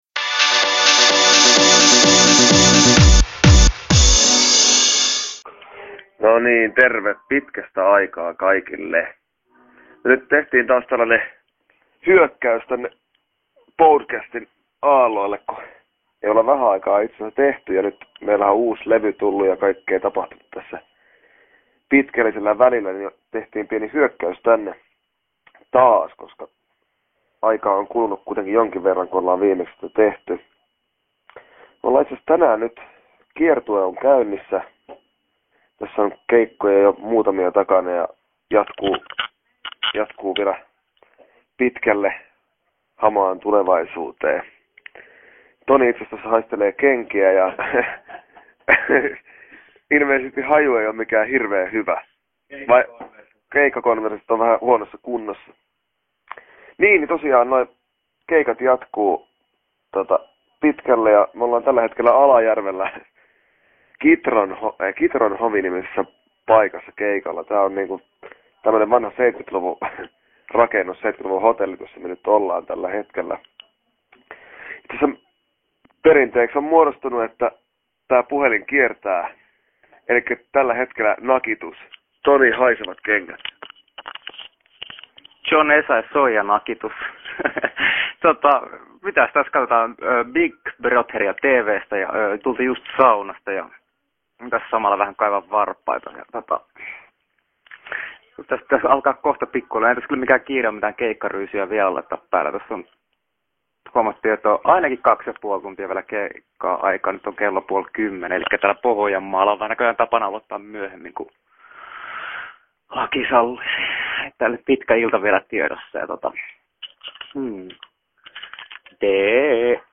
Technicolour teki lähes 20 vuotta sitten podcastia Staraan, ja tästä alta voit kuunnella yhtyeen eräät terveiset marraskuulta 2006.